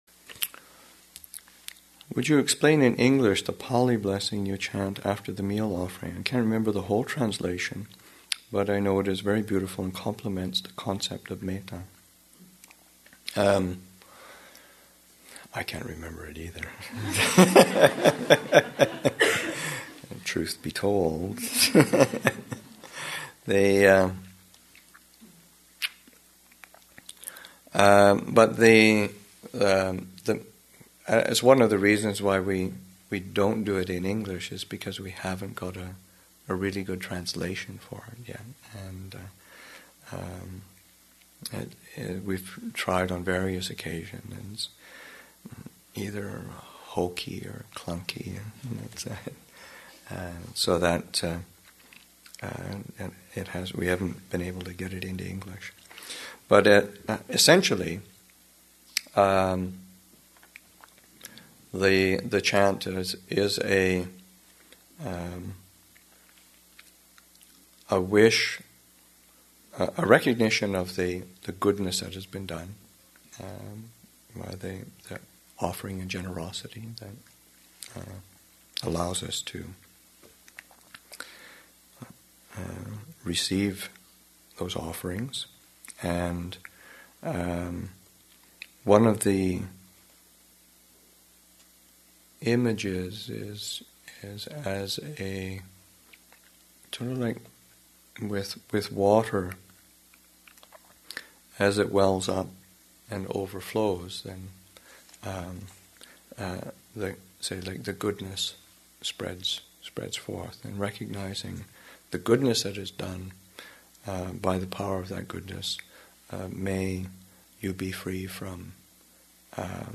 Metta Retreat, Session 4 – Sep. 12, 2008